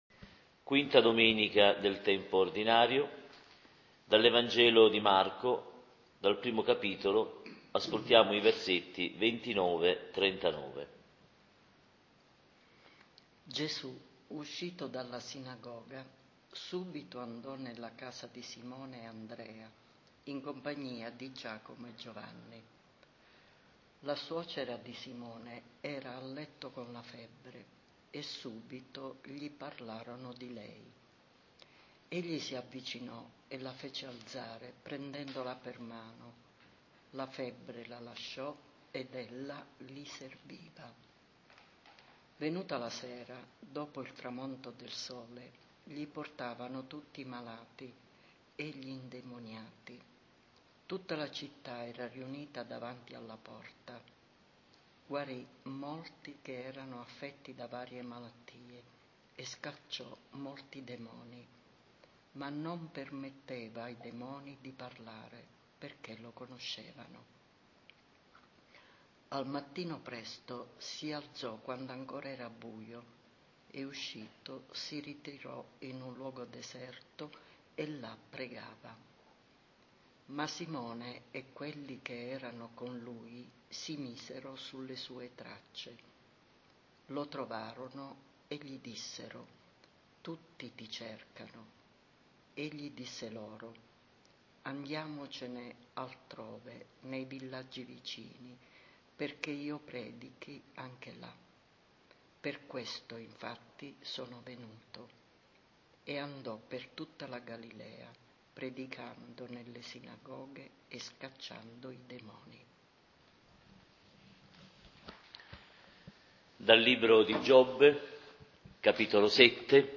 LECTIO DIVINA della DOMENICA «DELLE GUARIGIONI», V per l’Anno B